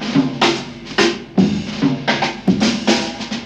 JAZZ BREAK 1.wav